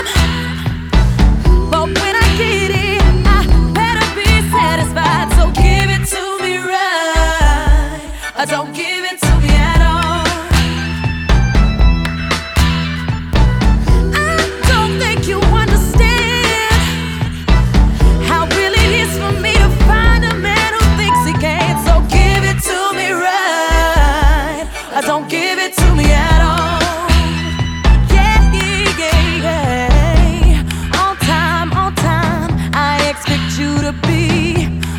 Жанр: Хип-Хоп / Рэп / Поп музыка / Рок / R&B / Соул